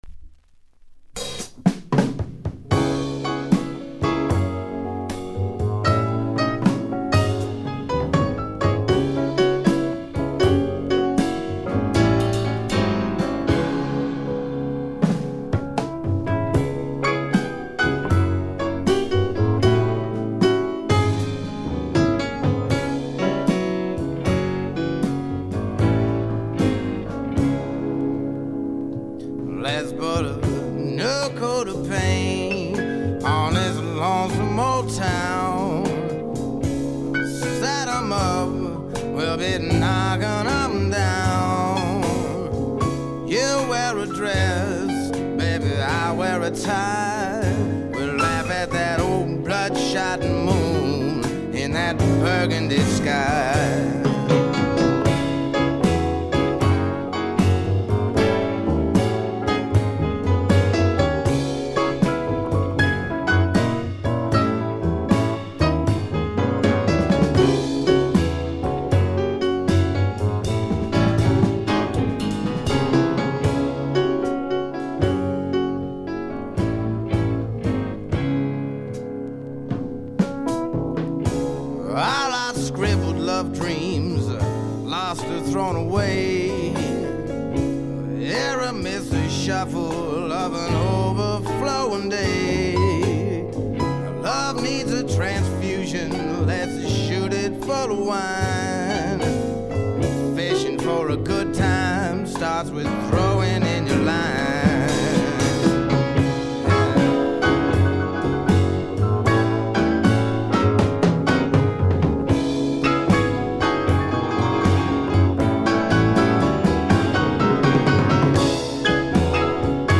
軽微なチリプチ程度。
メランコリックでぞっとするほど美しい、初期の名作中の名作です。
試聴曲は現品からの取り込み音源です。
vocals, piano, guitar
tenor saxophone